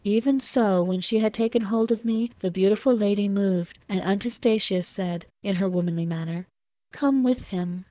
We take 100 test samples from the dev-clean subset of LibriTTS for testing.